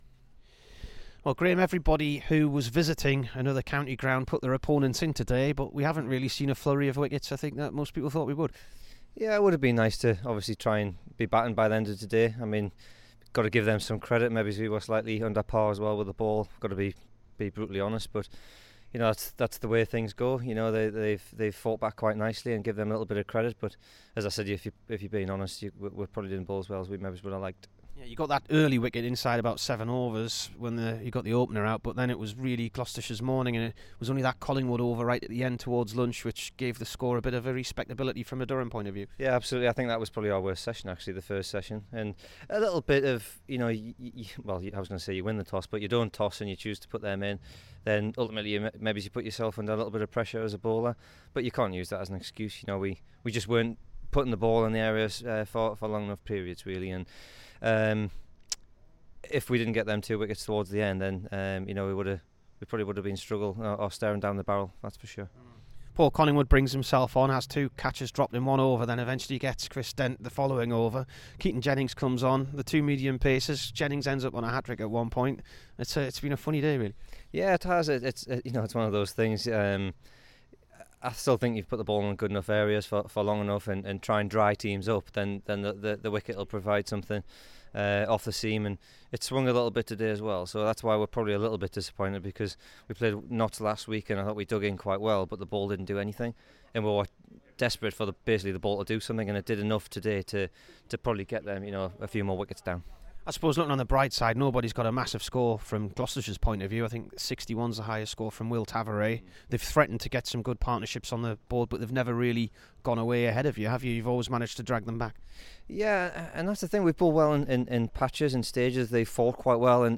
Here is the Durham bowler after day one away to Gloucestershire.